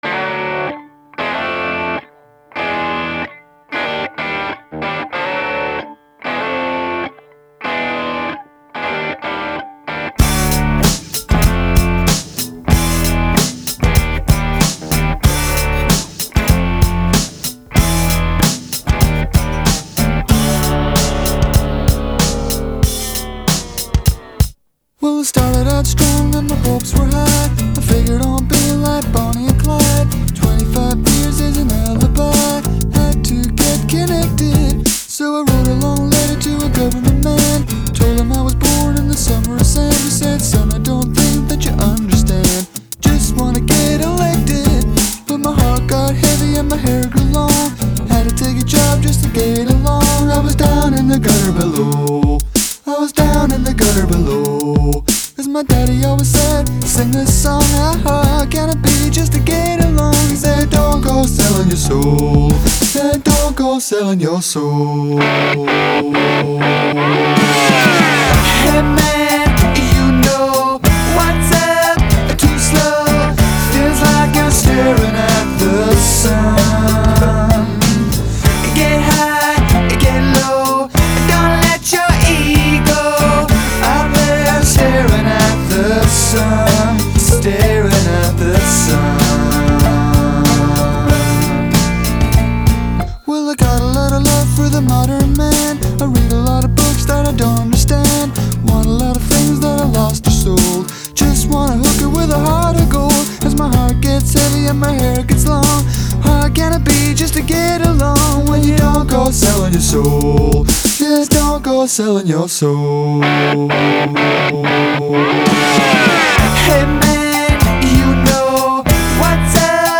Irish multi-instrumentalist/producer
initially recorded on a home multi-track cassette recorder.
kicks off with a distinctive guitar hook